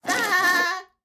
Minecraft Version Minecraft Version snapshot Latest Release | Latest Snapshot snapshot / assets / minecraft / sounds / mob / goat / screaming_milk4.ogg Compare With Compare With Latest Release | Latest Snapshot
screaming_milk4.ogg